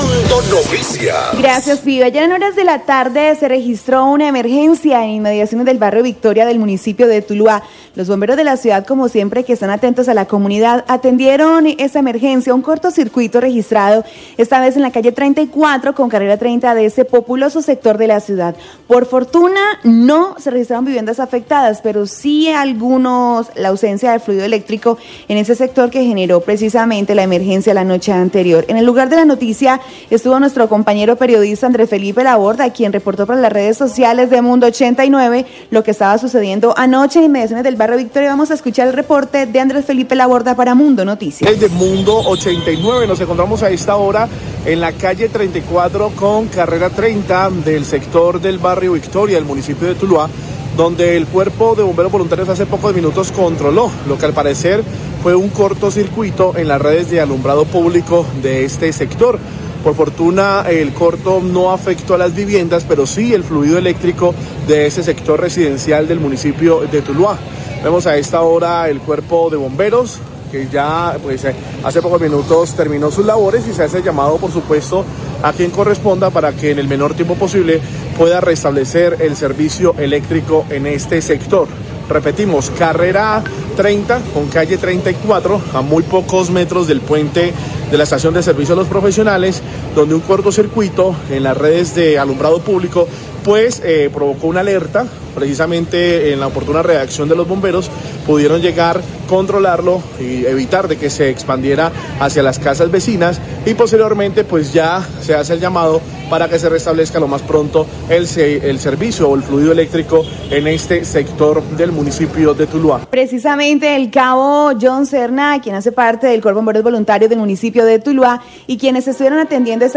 Radio
MUNDO NOTICIAS-TULUÁ